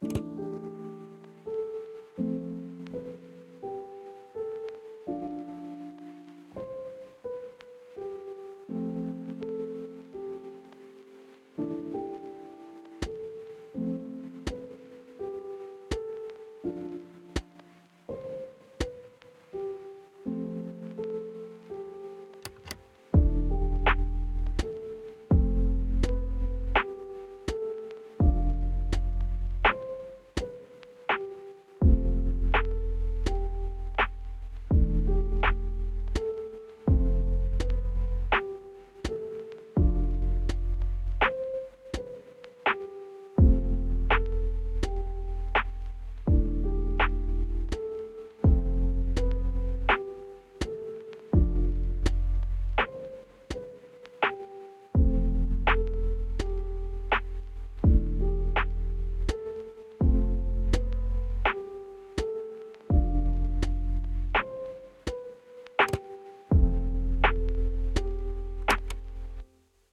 Press Play Lofi track
At the time, I was trying to create some homey lofi for a start menu, and came up with this and a few others (Most are on my SoundCloud).